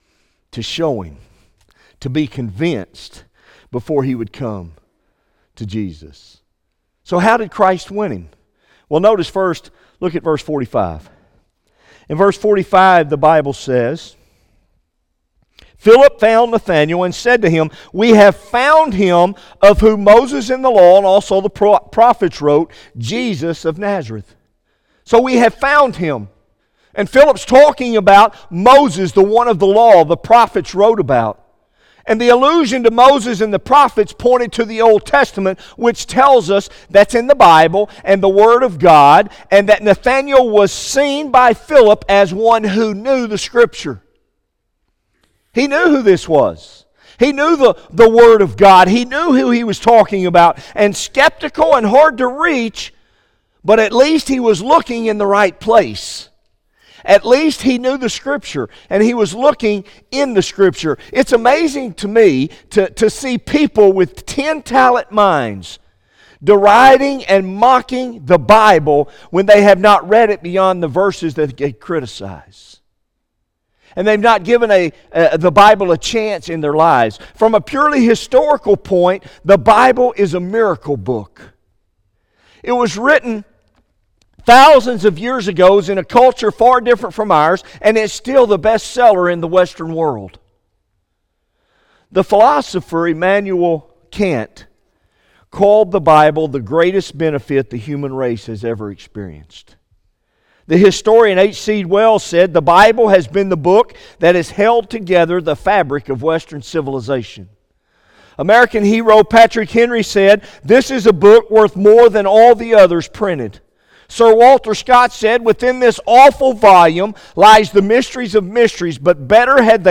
by Office Manager | Jan 9, 2017 | Bulletin, Sermons | 0 comments
Evening Worship